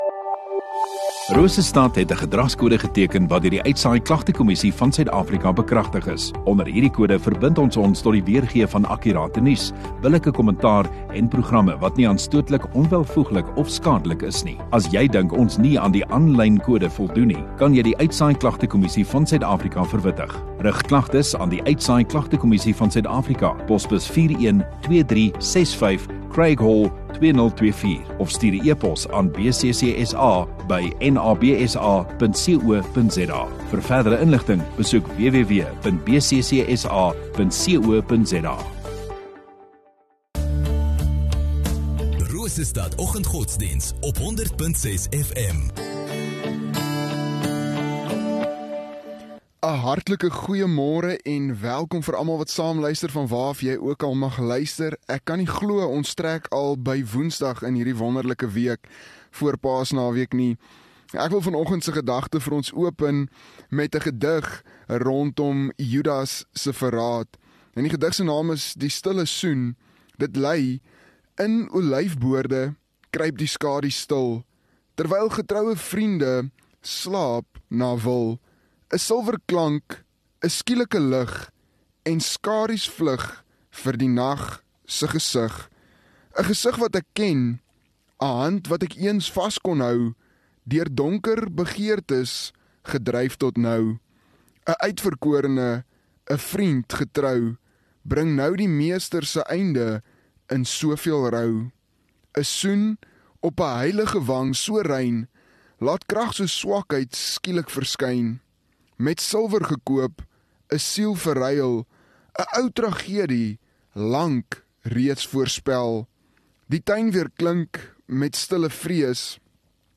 1 Apr Woensdag Oggenddiens